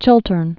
(chĭltərn)